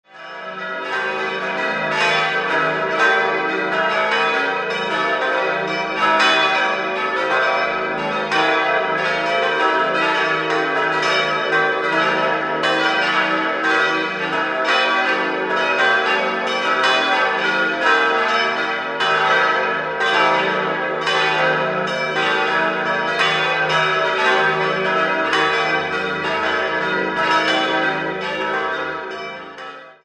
5-stimmiges ausgefülltes und erweitertes E-Moll-Geläute: e'-g'-a'-h'-d''